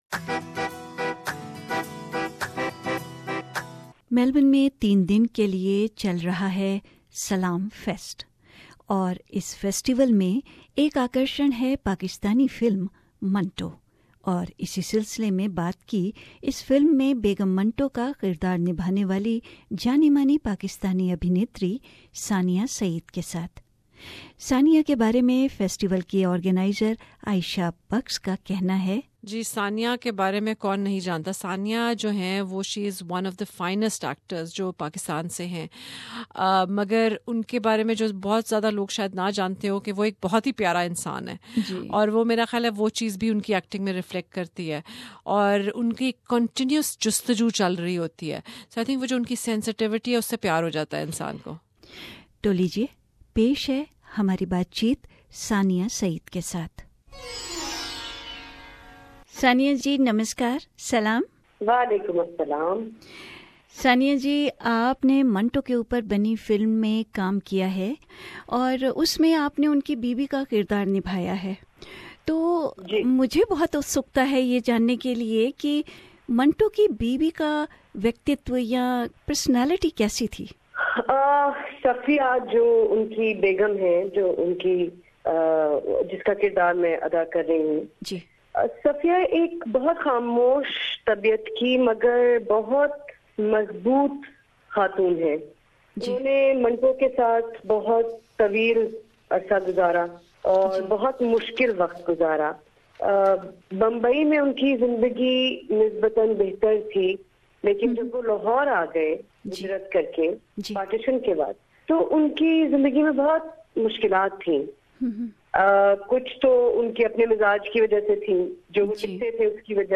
Speaking with SBS Hindi radio, she added, “ ...The life of a wife of any activist is always a difficult one and full of challenges and Safiya was not an exception …”